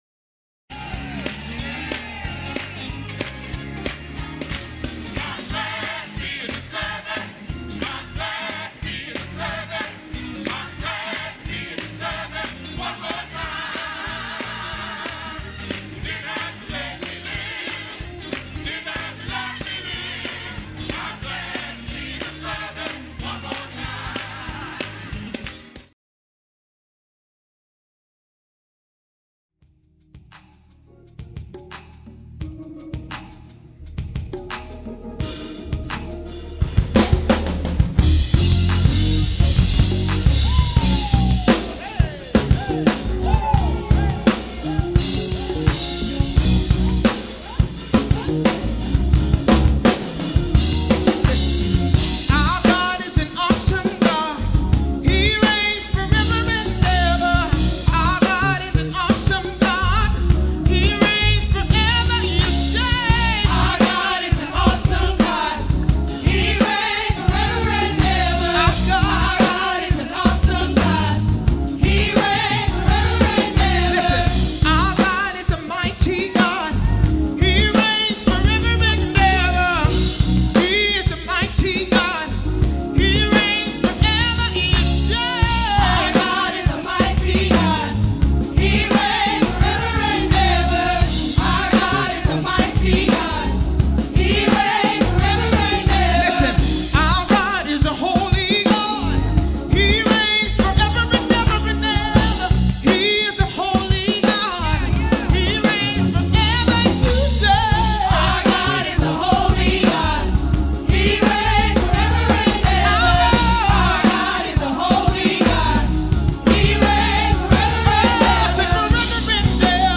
7:30 A.M. Service: You Are Redeemed